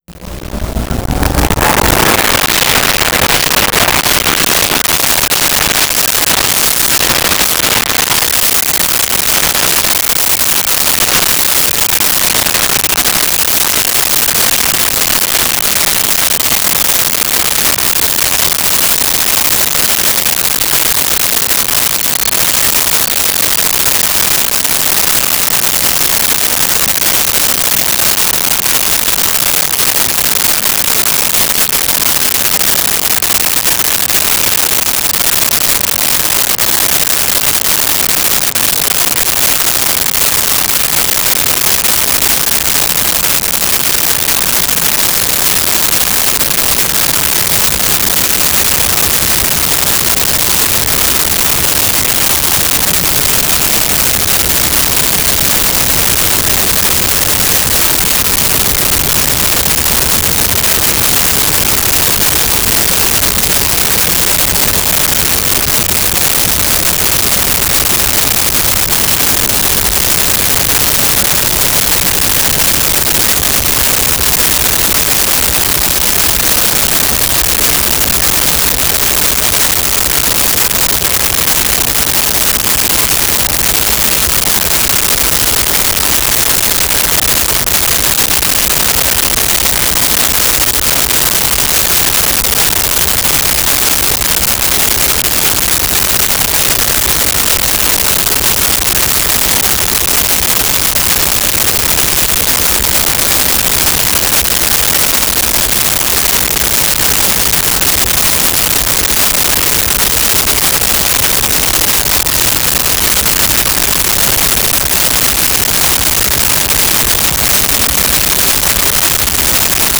Military Helicopter Start Idle Away
Military Helicopter Start Idle Away.wav